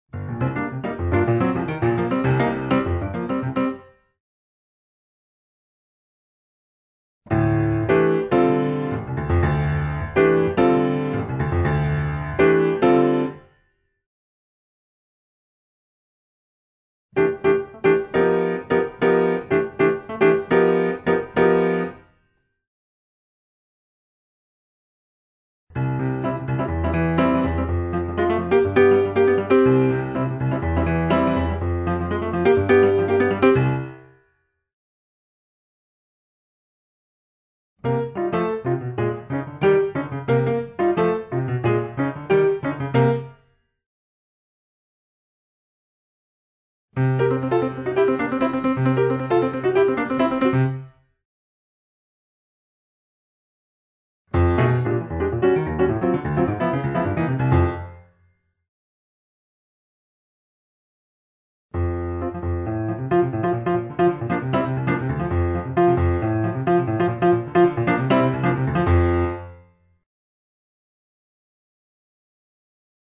Levadas.